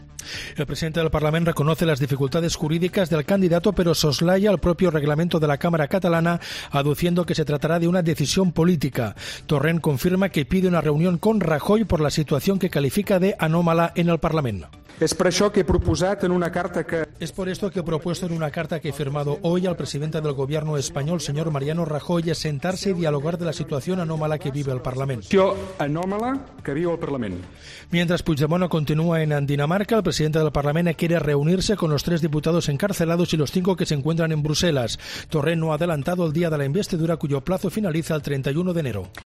AUDIO: Escucha la crónica